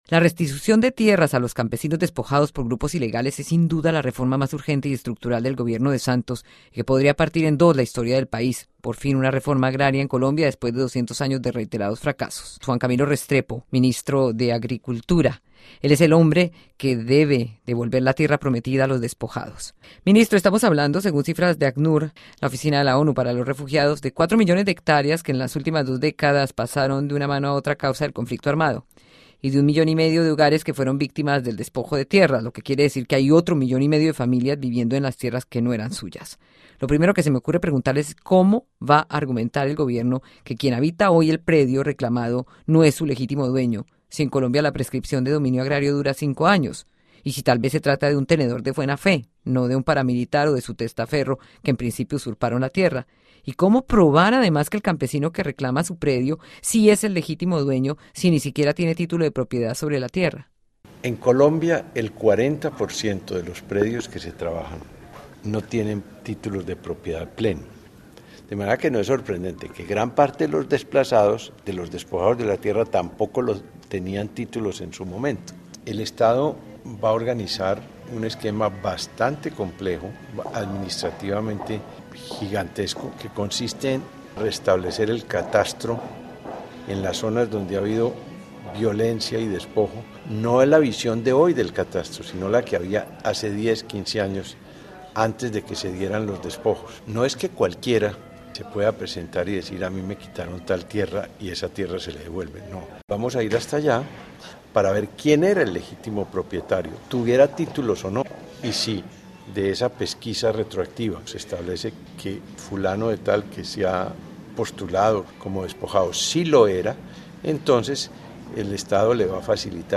El ministro de Agricultura habló sobre la restitución de tierras a los campesinos despojados por grupos ilegales. Escuche el informe de Radio Francia Internacional.